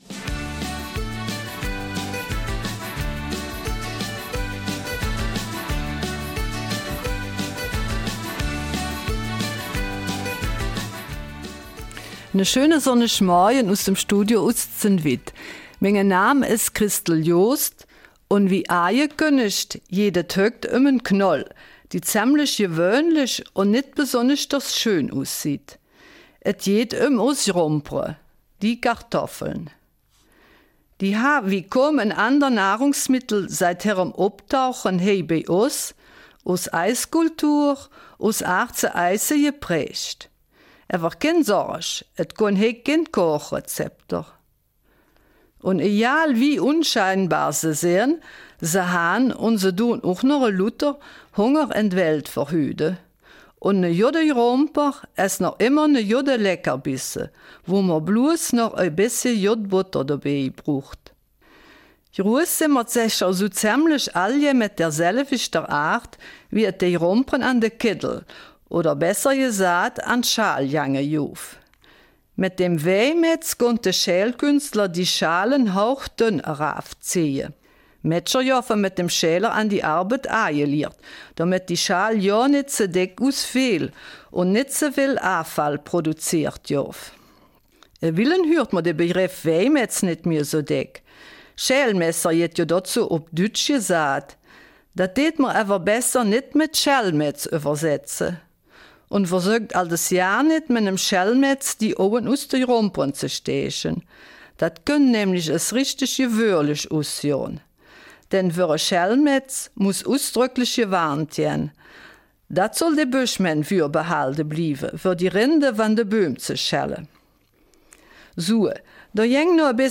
Eifeler Mundart: Die Kartoffel